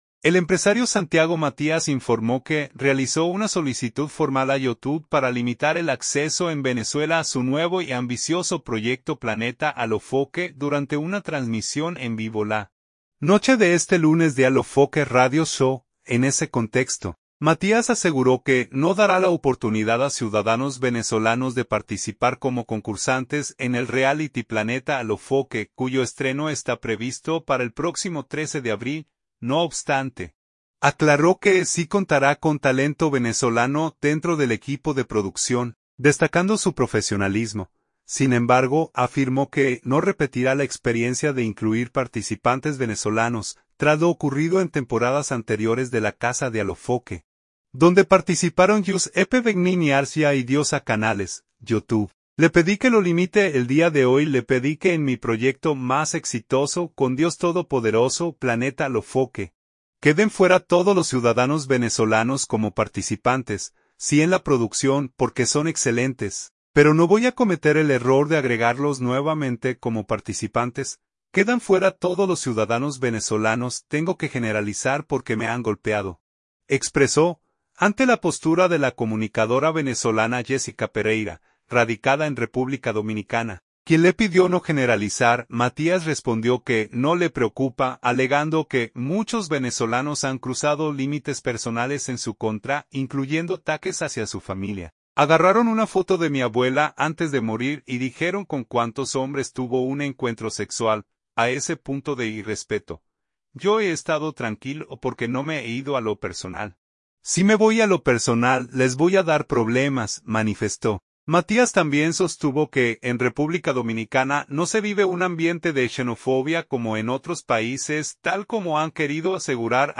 El empresario Santiago Matías informó que realizó una solicitud formal a YouTube para limitar el acceso en Venezuela a su nuevo y ambicioso proyecto Planeta Alofoke durante una transmisión en vivo la noche de este lunes de Alofoke Radio Show.